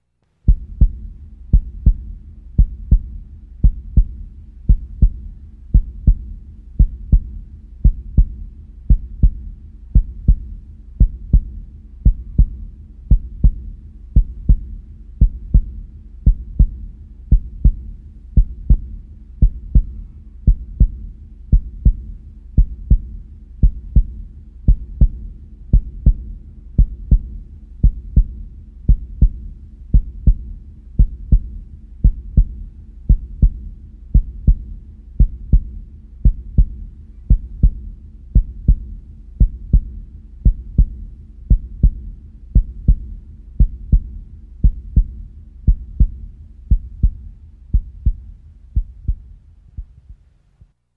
جلوه های صوتی
دانلود آلبوم صدای ضربان قلب انسان از ساعد نیوز با لینک مستقیم و کیفیت بالا